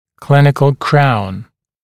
[‘klɪnɪkl kraun][‘клиникл краун]клиническая коронка